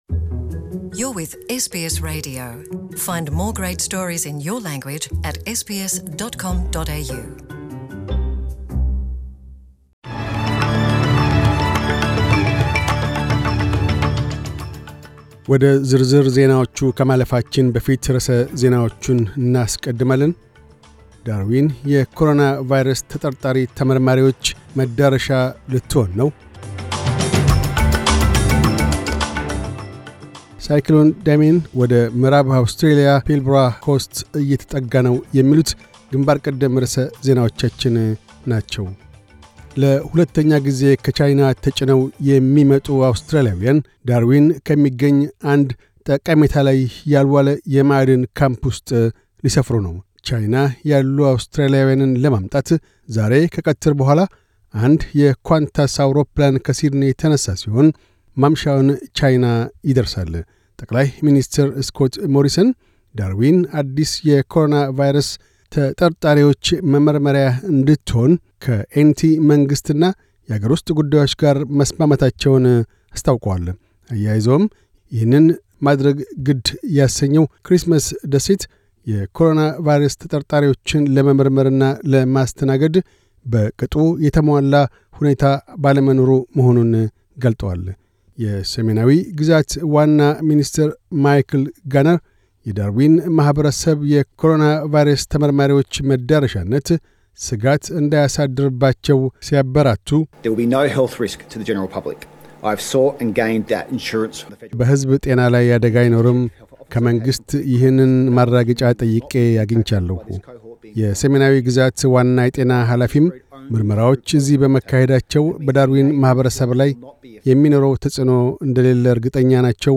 News Bulletin 0207